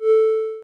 Techmino/media/effect/chiptune/spin_0.ogg at fd3910fe143a927c71fbb5d31105d8dcaa0ba4b5
spin_0.ogg